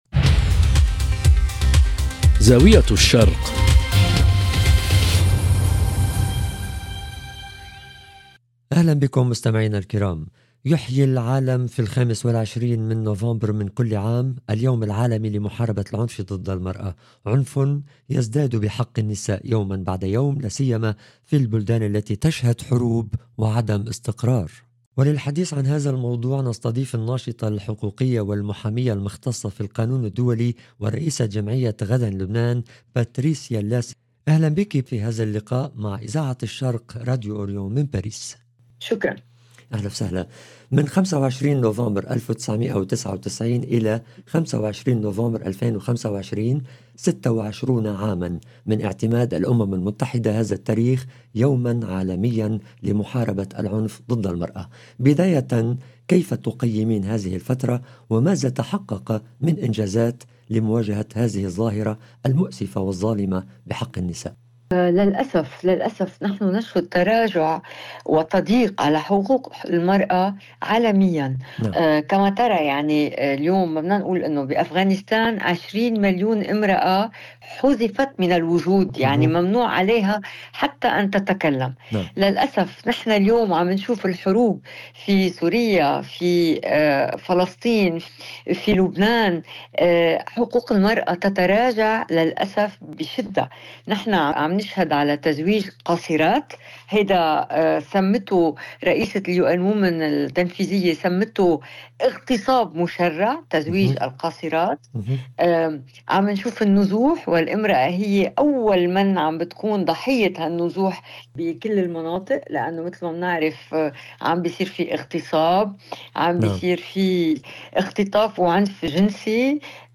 في اليوم العالمي لمناهضة العنف ضد المرأة حوار